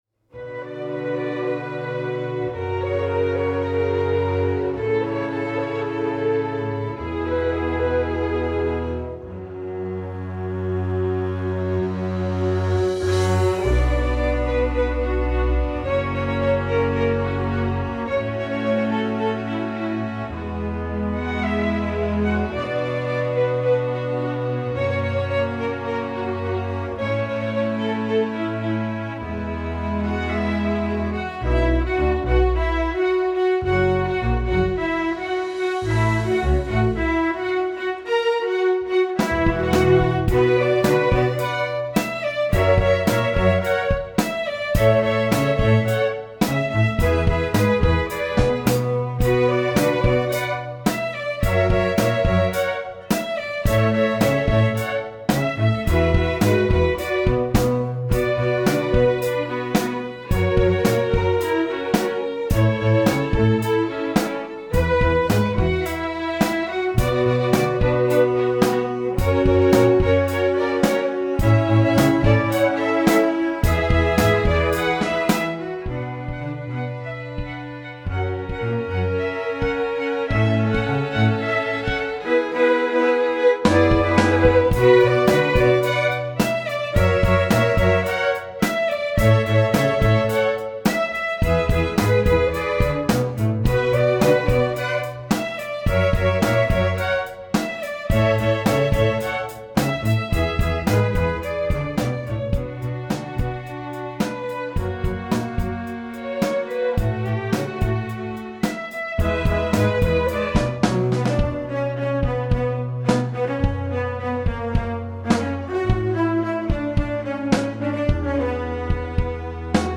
This piece has all the elements of a top-notch pop tune.